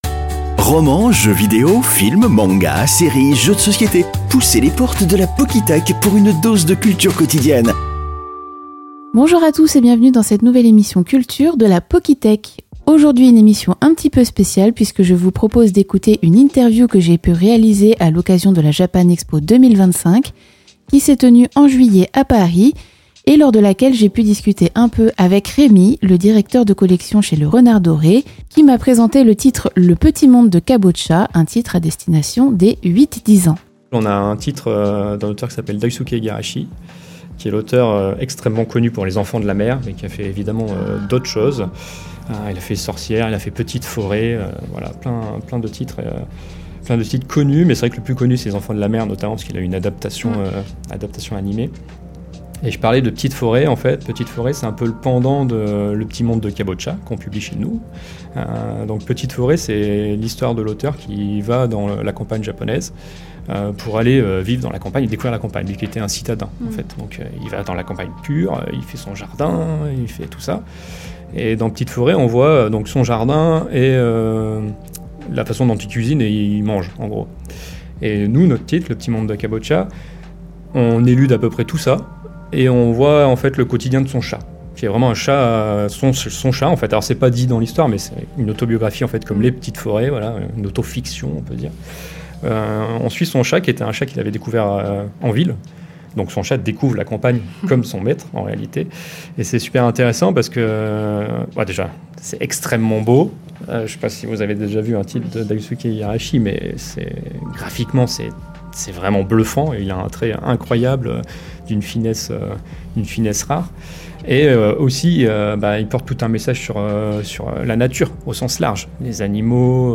La Pockythèque - Interview Le Renard doré (partie 4)